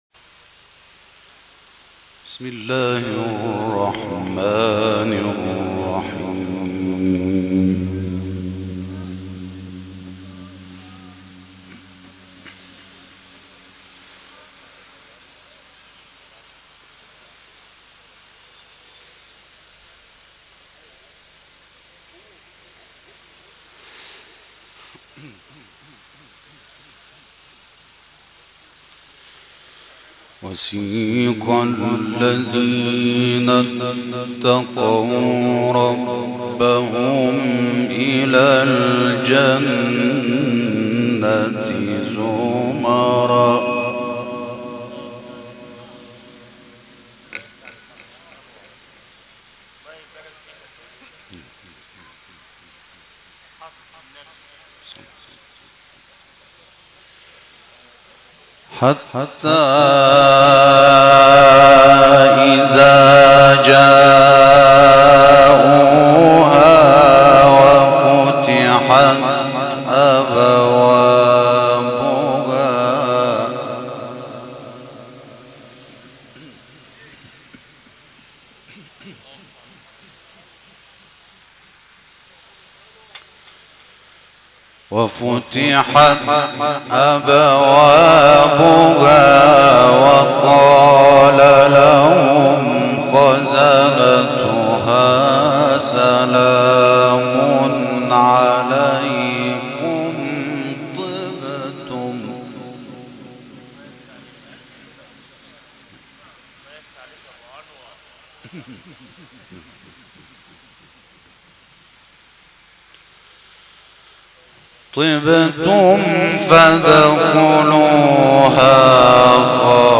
تلاوت «انور شحات» از سوره زمر
این تلاوت 12 شهریور ماه در مجلس عزا اجرا شده است.